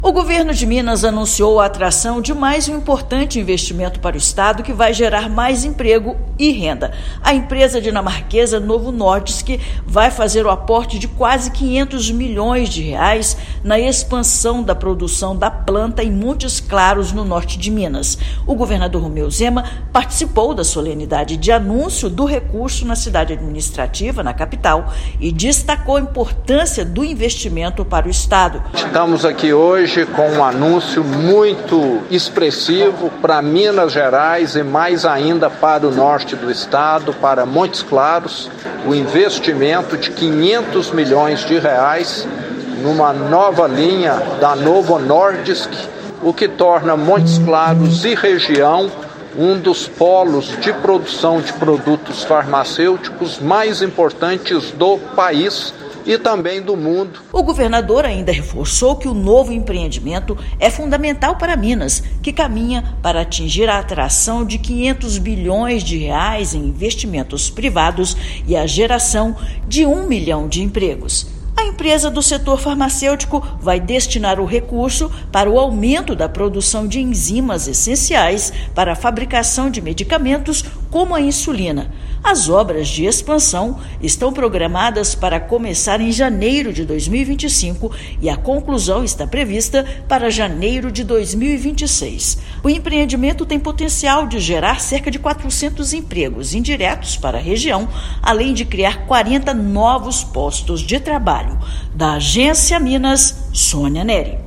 [RÁDIO] Governo de Minas atrai investimento de R$ 500 milhões para expansão de fábrica de enzimas para insulina e medicamentos em Montes Claros
Obras de expansão devem gerar cerca de 400 empregos diretos e indiretos, além de 40 novos postos de trabalho. Ouça matéria de rádio.